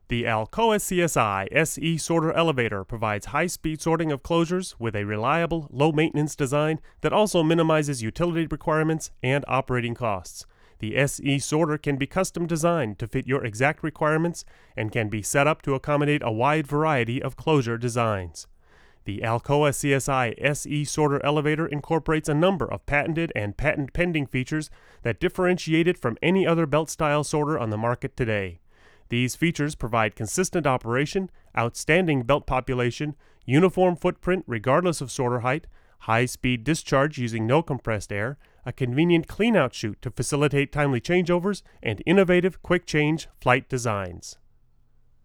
These are raw recordings - I've done nothing with EQ or compression on these files.
The Seb was set with the 15dB pad - no HF EQ or LF EQ.
Oh, and stick a pencil in front of the mic you end up using - there are plosives!